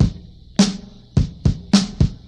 • 105 Bpm Modern Breakbeat A# Key.wav
Free drum groove - kick tuned to the A# note. Loudest frequency: 1031Hz
105-bpm-modern-breakbeat-a-sharp-key-FOc.wav